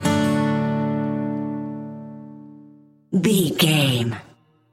Ionian/Major
Slow
acoustic guitar
bass guitar